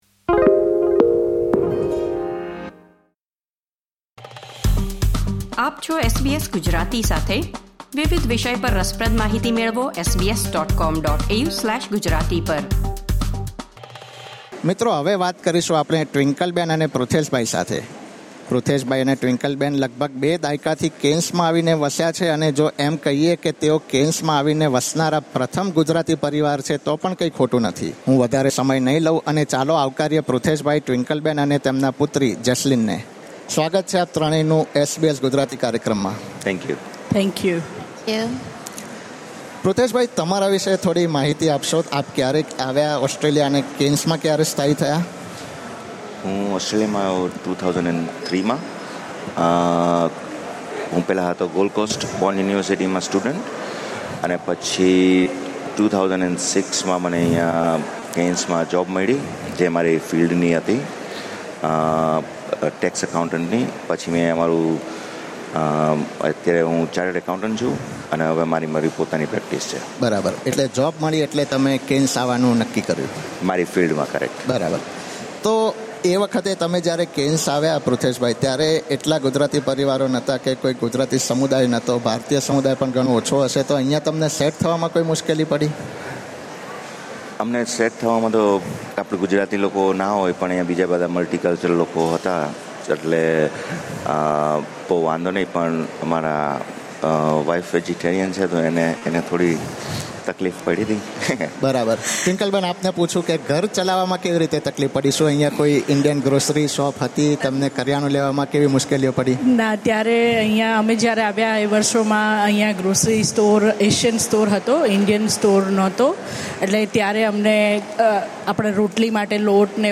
કેઇન્સમાં યોજવામાં આવેલા ખાસ રેડિયો કાર્યક્રમ દરમિયાન લેવામાં આવેલી તેમની મુલાકાતમાં રસપ્રદ માહિતી મેળવીએ.